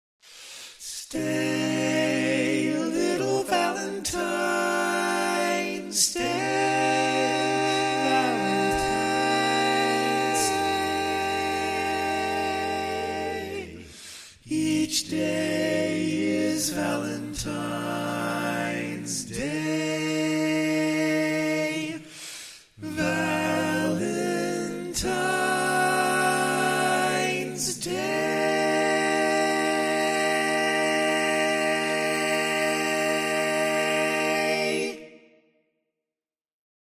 Key written in: B Minor
Type: Barbershop